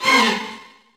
Cardiak String Stab.wav